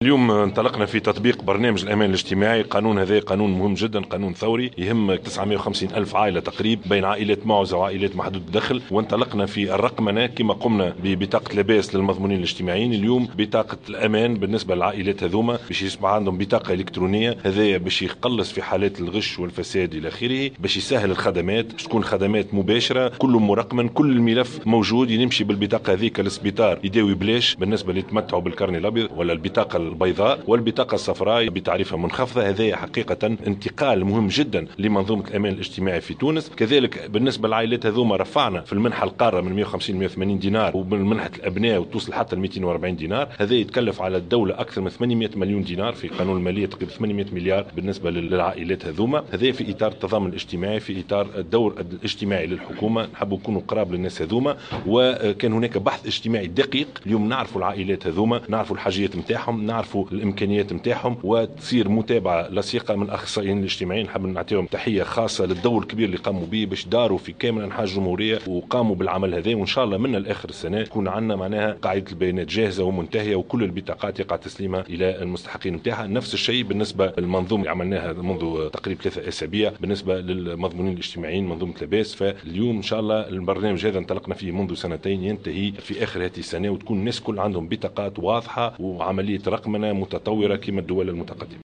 و قال الشاهد في تصريح لمراسلة الجوهرة "اف ام" إنه سيتم الشروع في رقمنة الخدمات الصحية لفائدة هذه الفئات من خلال بطاقة الأمان الالكترونية و التي ستسهل الخدمات وتحد من حالات الغش و الفساد وستعوض البطاقتين البيضاء والصفراء.